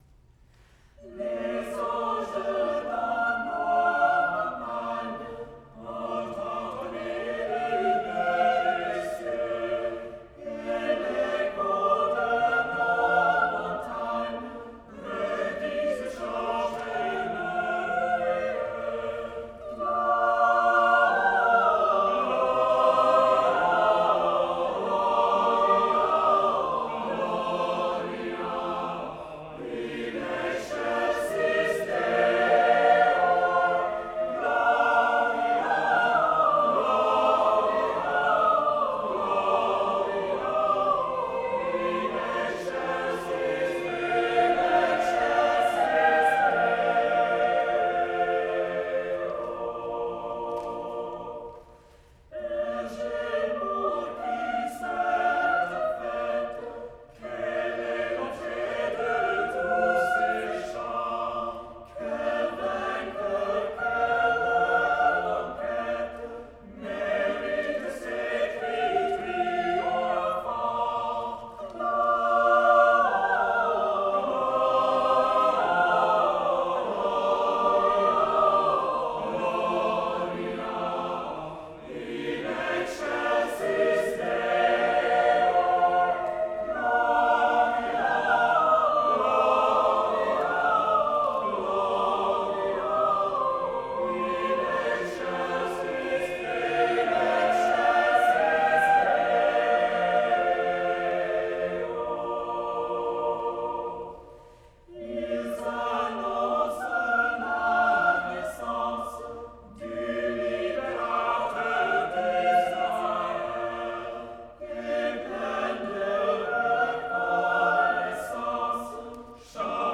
choeur de chambre